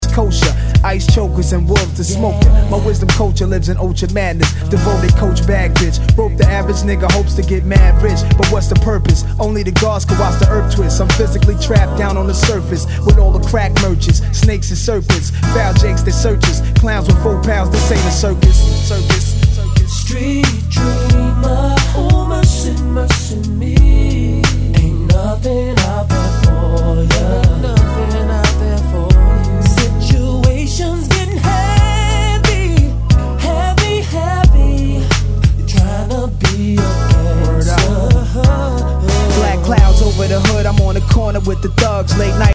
Tag       HIP HOP NEWSCHOOL